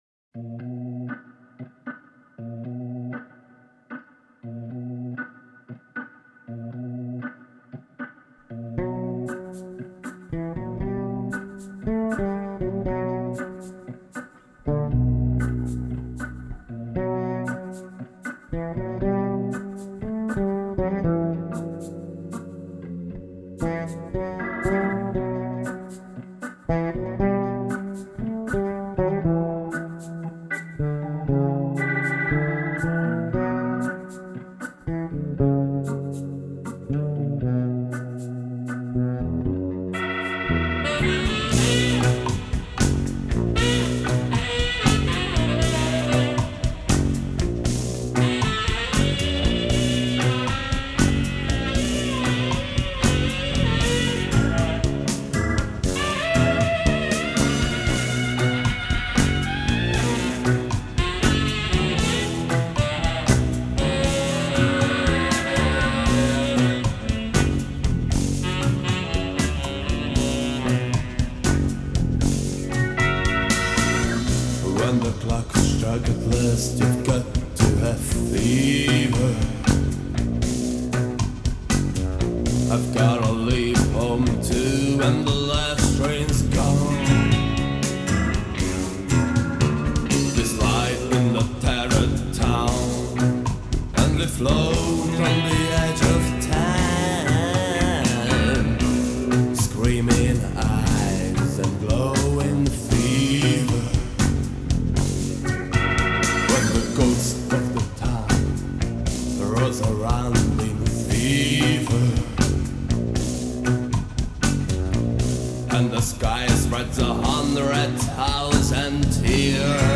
zongora, orgona, vokál
gitár, ének
basszusgitár
szaxofon
percussion, vokál
harmónika
slide gitár